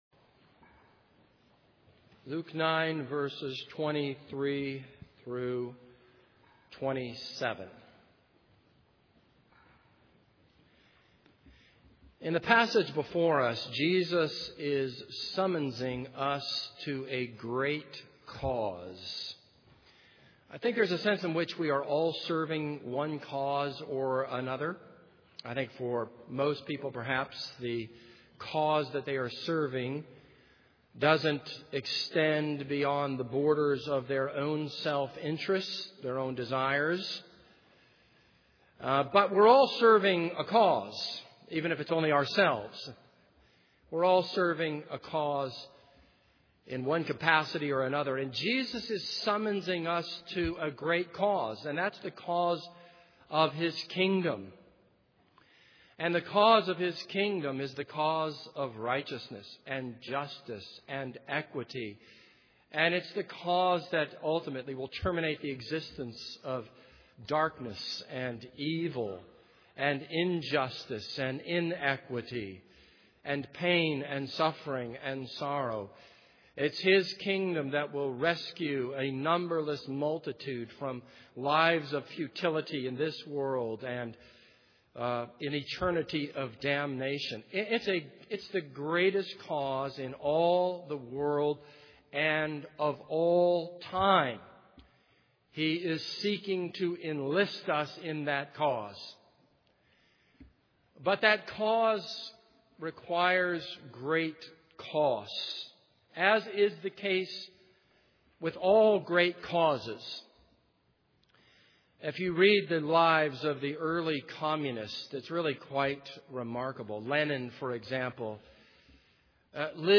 This is a sermon on Luke 9:23-27.